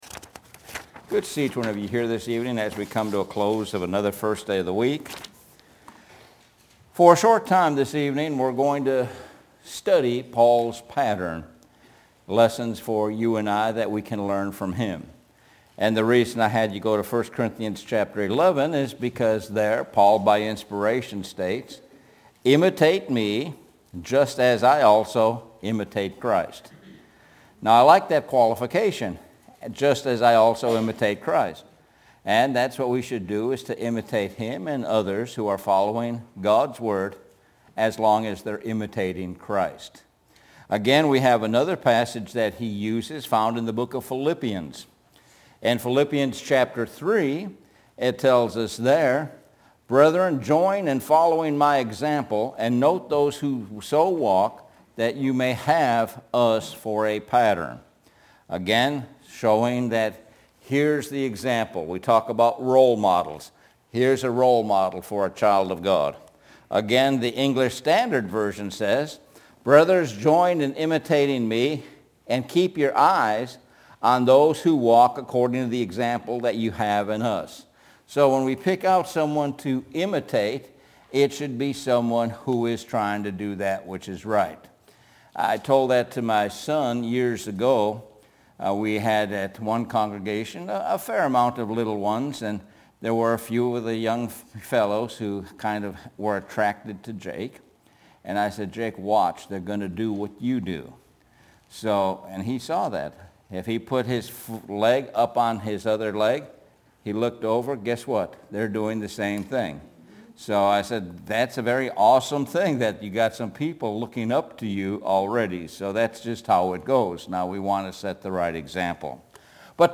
Sun PM Sermon – Paul’s Pattern